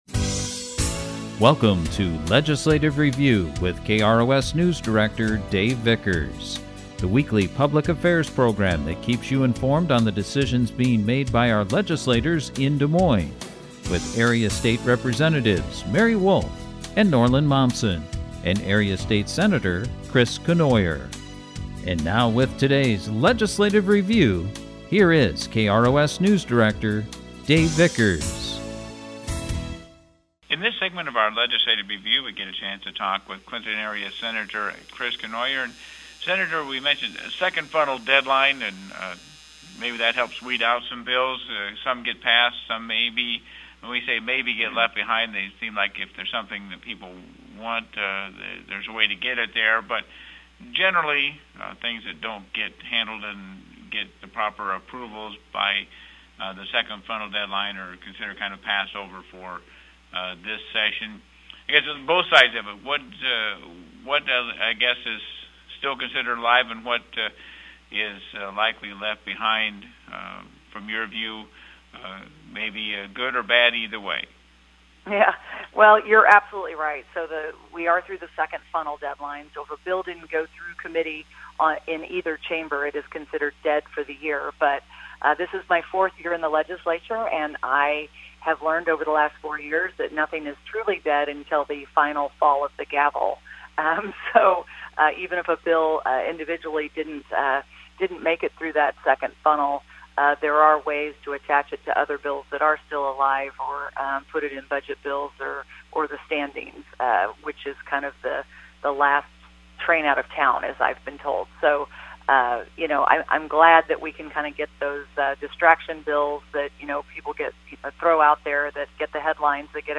Due to NCAA Tournament coverage there has been limited time to air our weekly LEGISLATIVE REVIEW Program-so here is this week’s conversations with Clinton area legislators